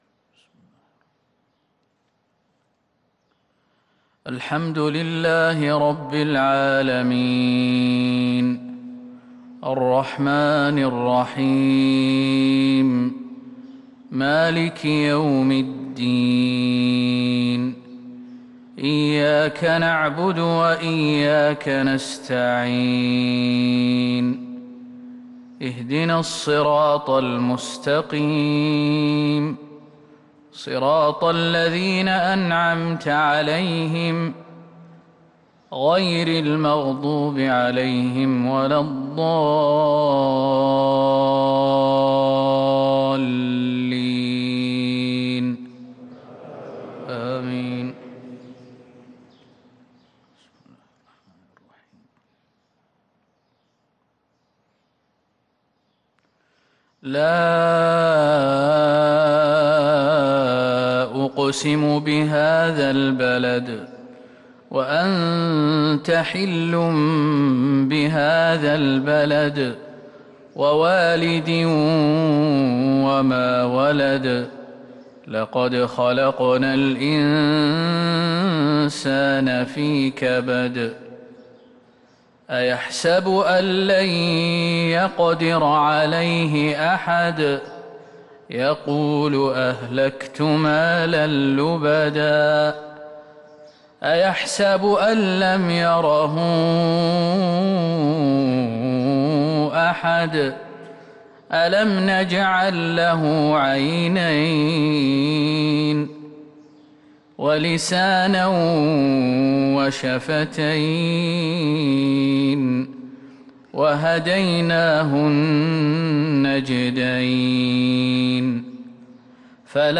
فجر السبت 2-8-1443هـ سورتي البلد و الليل | fajr prayer from surat Al-Balad & Al-Layl 5-3-2022 > 1443 🕌 > الفروض - تلاوات الحرمين